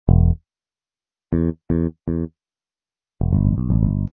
misc_bass00.mp3